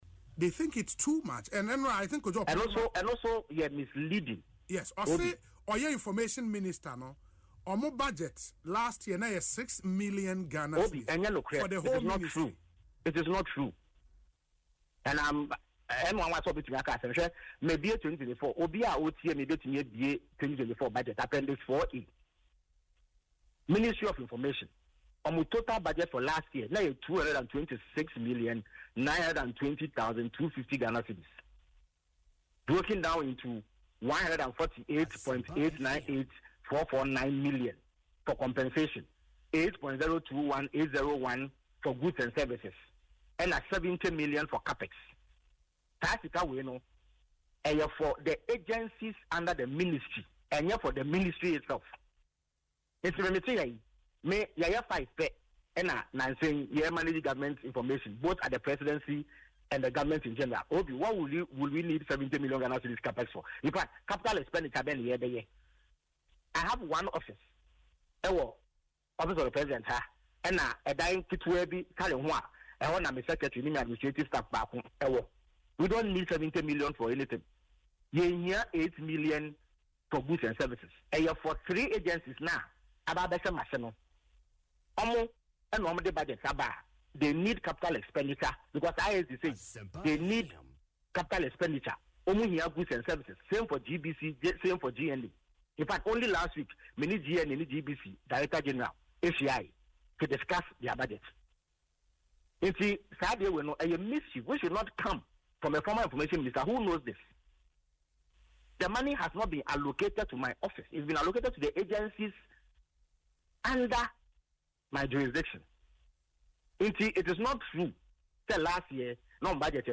However, speaking on Asempa FM’s Ekosii Sen, Mr. Kwakye Ofosu accused the former minister of being misleading.